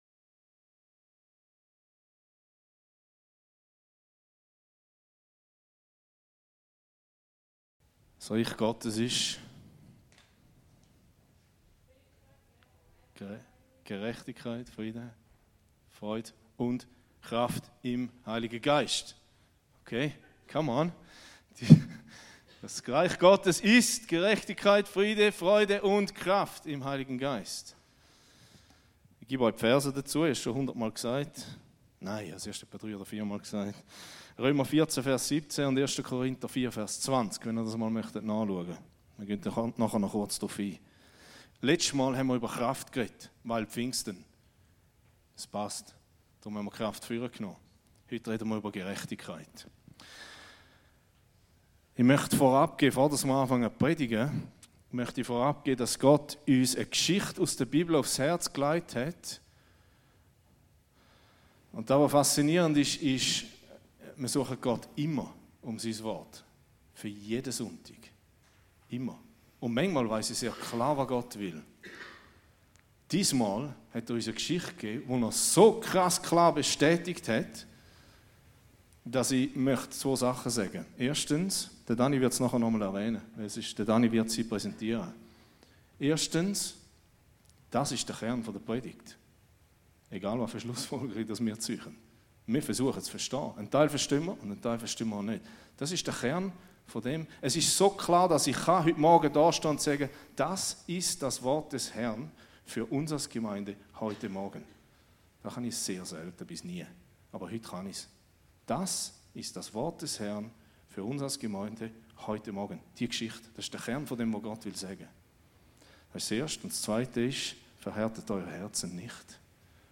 Hier hörst du die Predigten aus unserer Gemeinde.